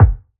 000_ODDMS_Kick_18.wav